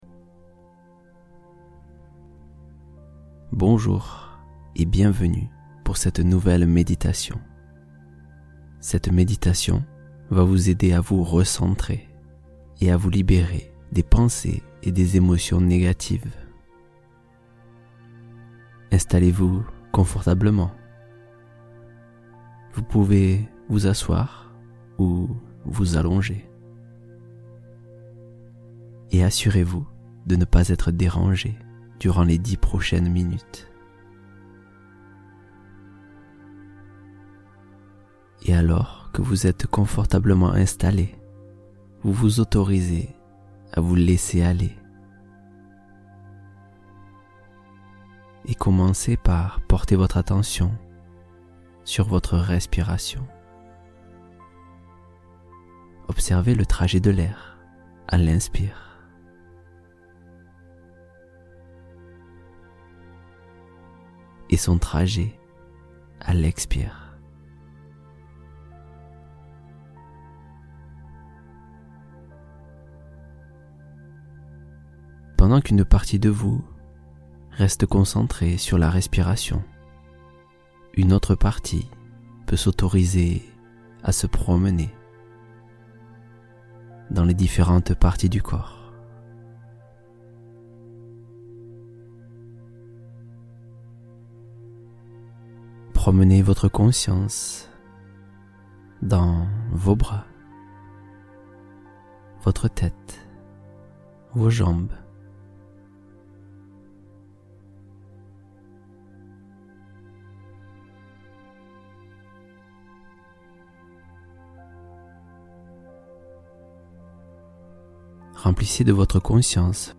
Clarté Décisive : Méditation pour prendre de meilleures décisions